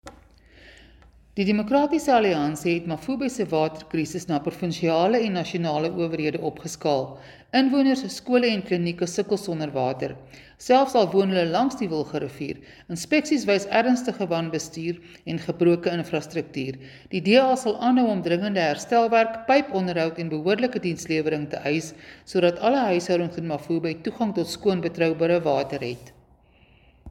Afrikaans soundbites by Cllr Suzette Steyn and